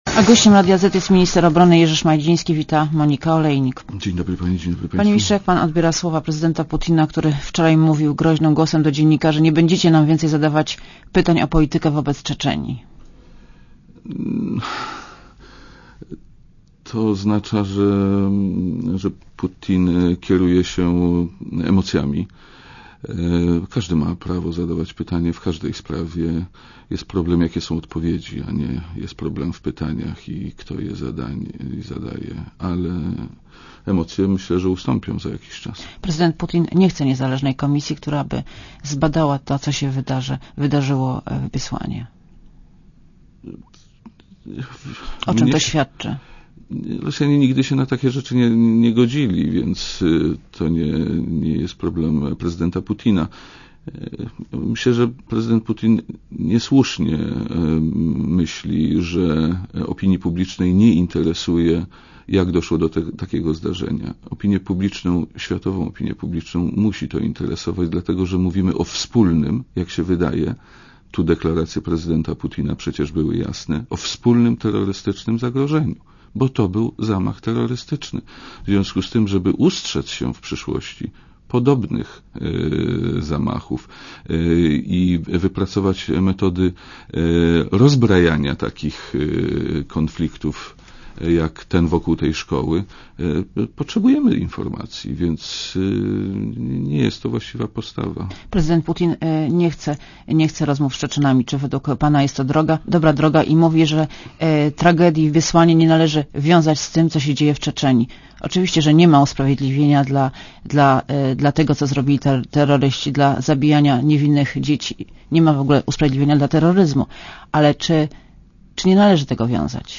Gościem Radia Zet jest minister obrony narodowej, Jerzy Szmajdziński.